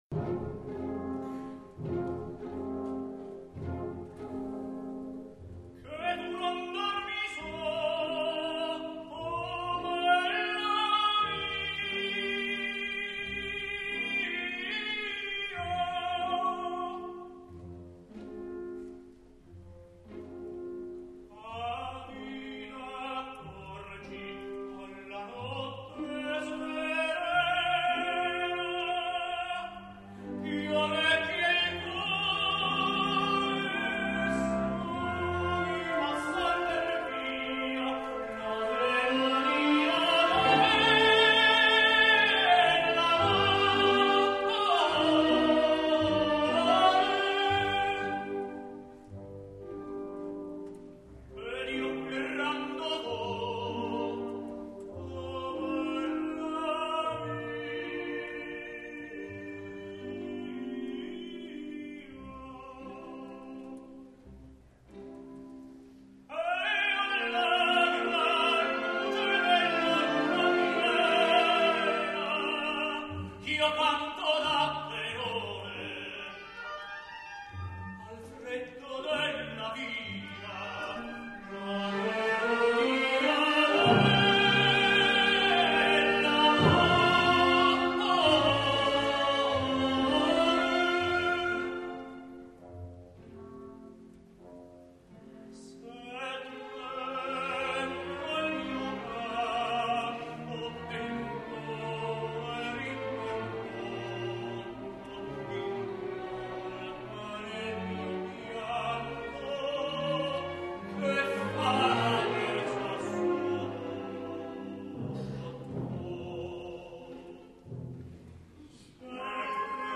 »commedia dell'arte / Berufsschauspielkunst« — {Verismo}
Florindo [Tenor]
Brighella [Tenor]
Pantalone de' Bisoquosi [Bass]